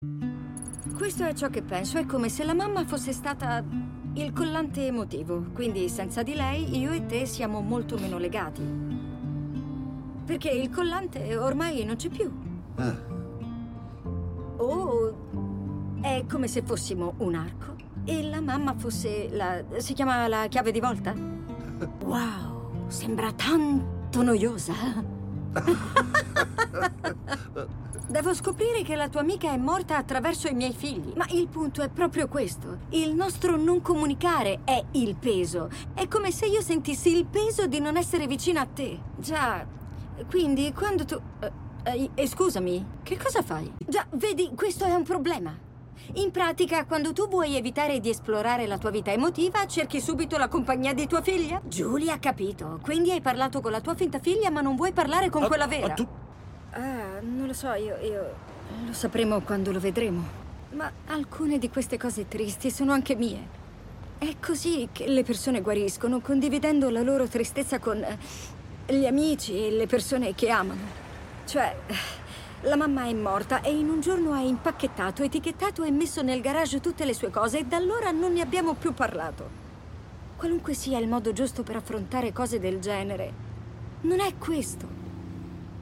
nel telefilm "A Man on the Inside", in cui doppia Mary Elizabeth Ellis.